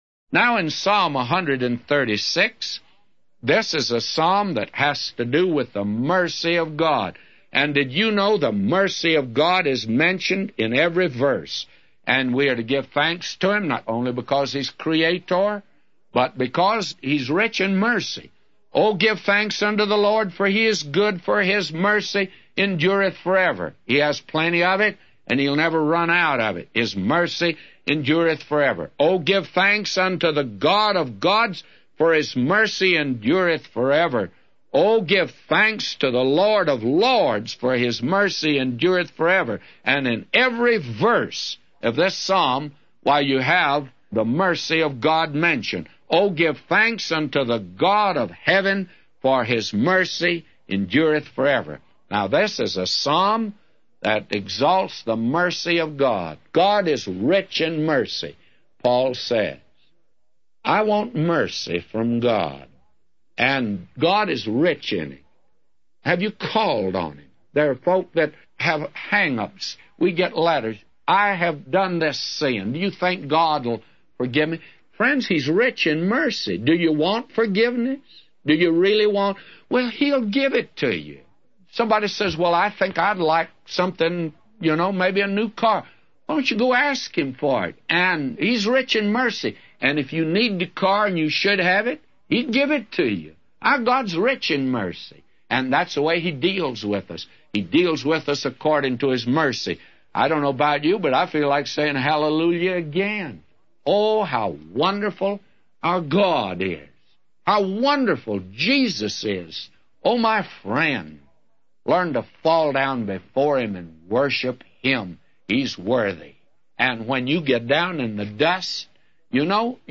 A Commentary